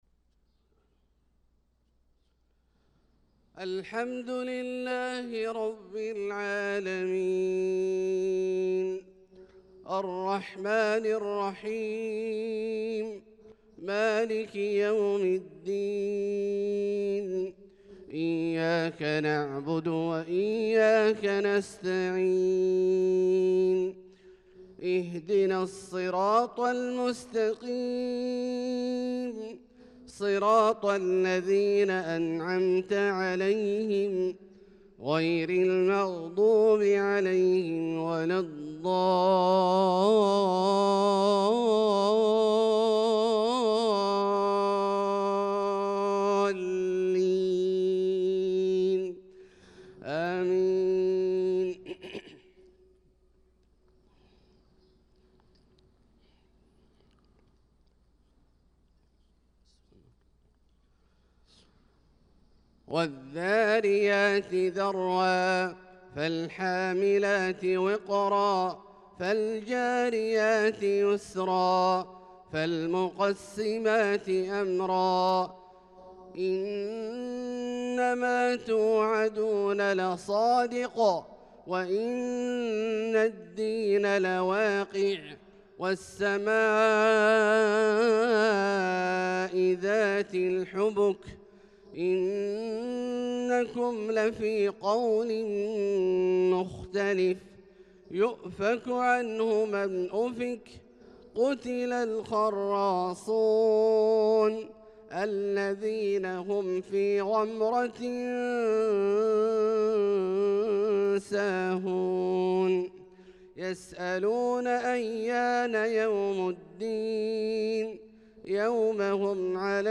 صلاة الفجر للقارئ عبدالله الجهني 28 شوال 1445 هـ